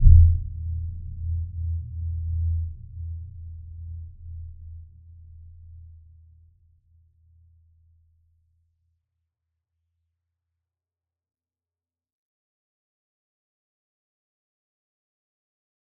Dark-Soft-Impact-E2-f.wav